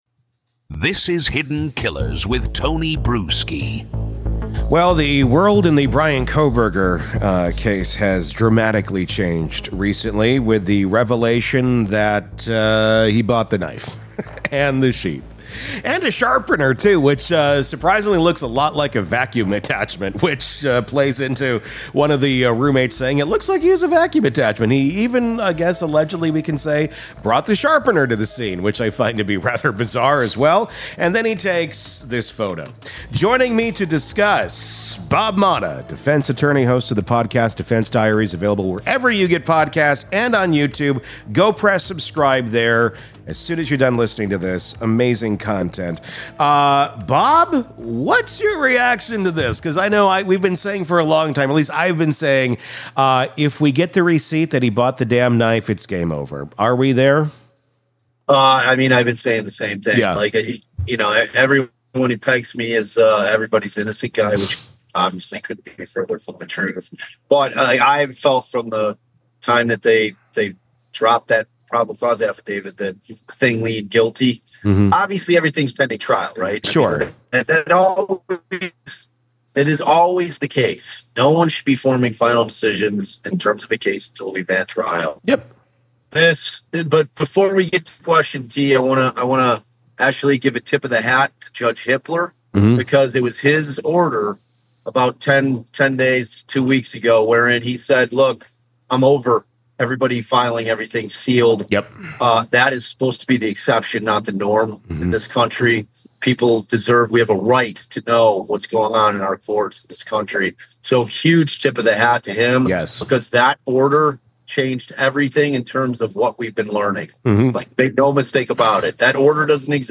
The conversation takes a twisty turn into the newly unsealed court filings, thanks to a judge finally putting transparency over secrecy. They also explore the eerie timing of the knife purchase—months before the murders—and whether that creates reasonable doubt or screams premeditation.